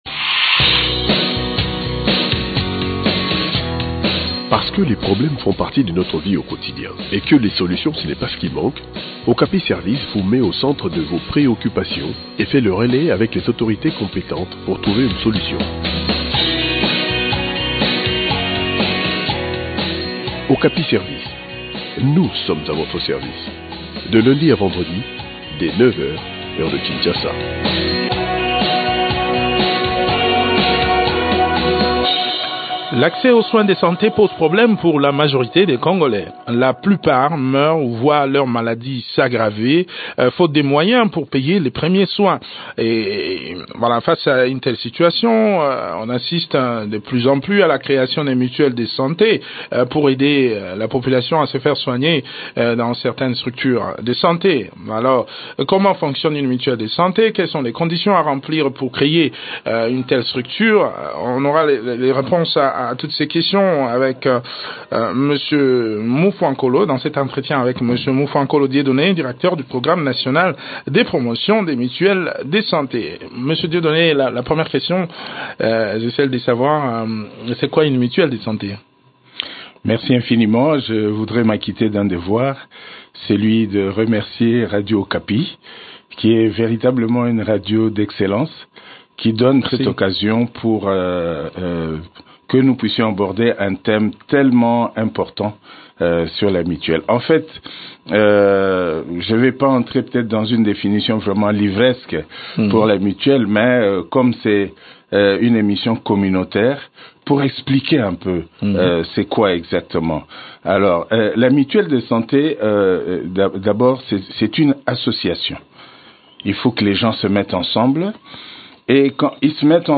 répond aux questions des auditeurs